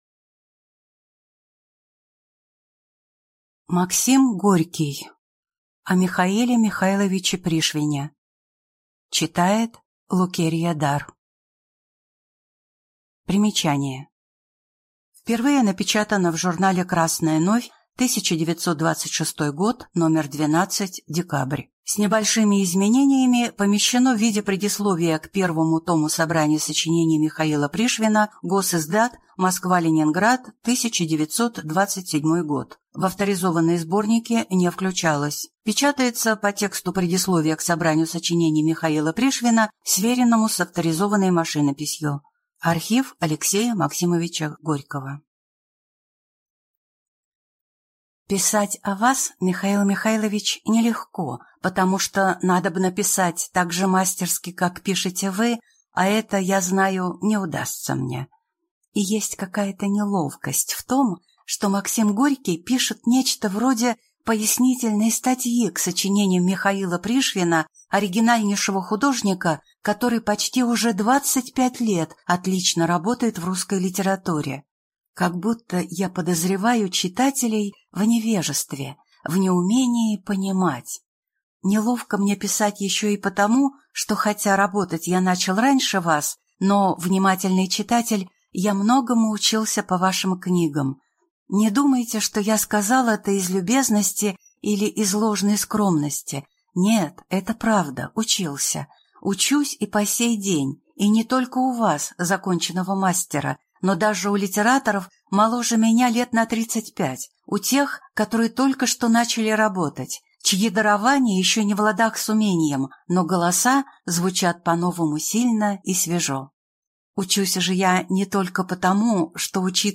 Аудиокнига О М. М. Пришвине | Библиотека аудиокниг